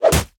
punch9.ogg